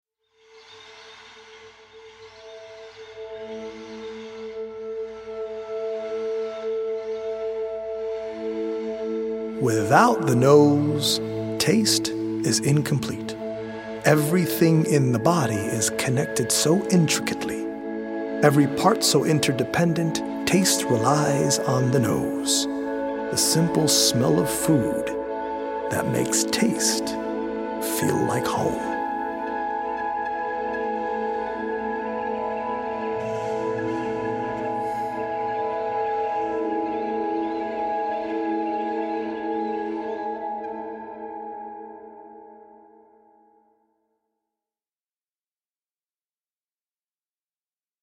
healing Solfeggio frequency music
EDM producer